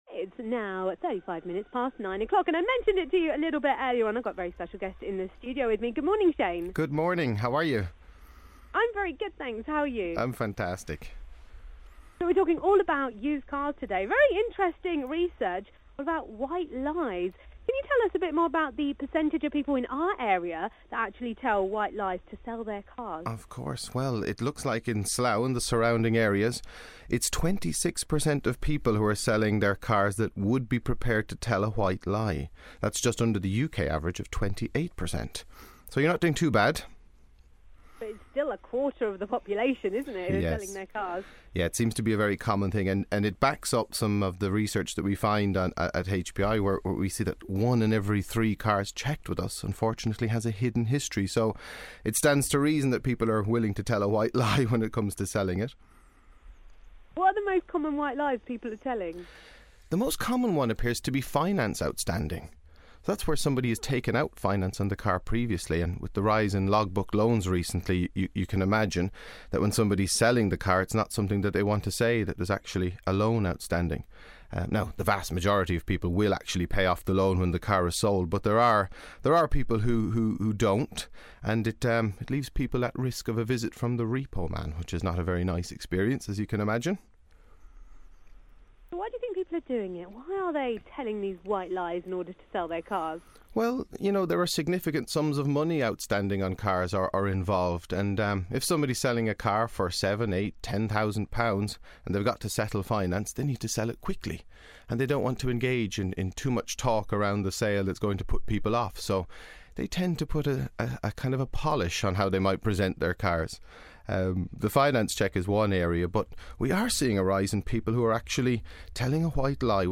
Interview with Asian Star